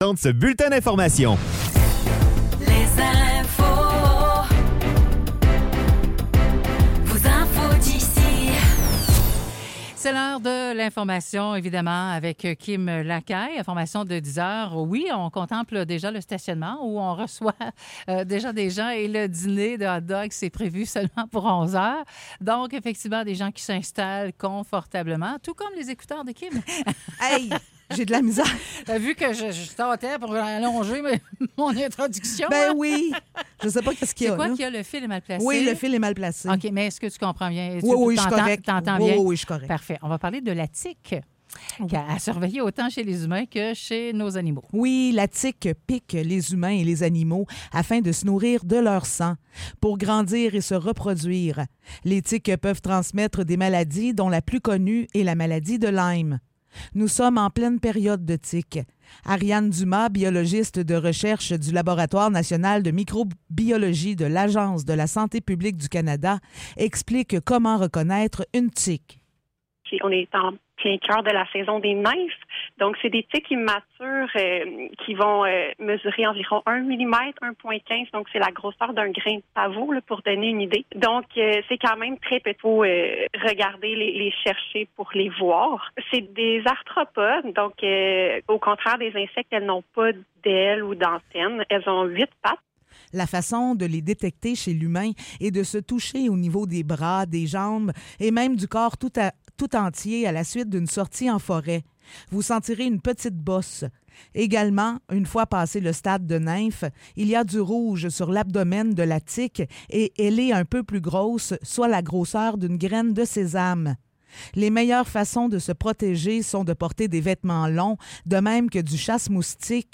Nouvelles locales - 14 juin 2024 - 10 h